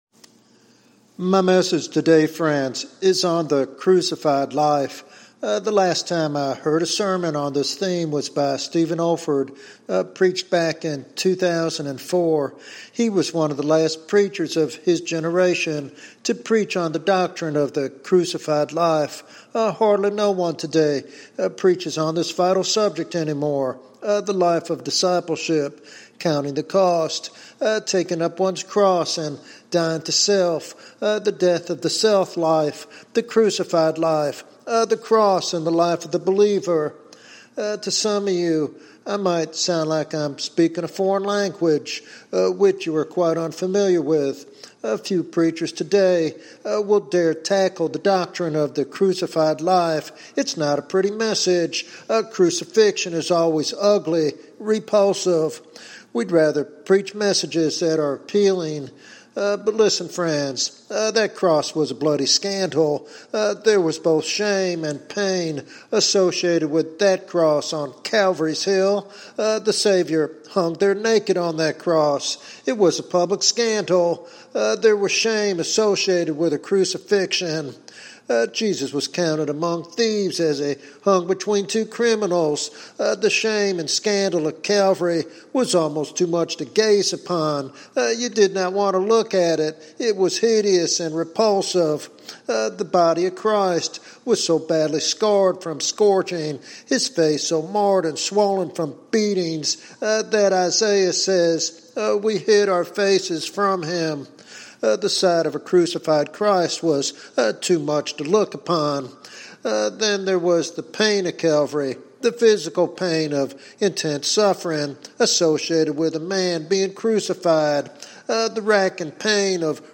This sermon is a compelling reminder that true Christianity is a life lived via the cross.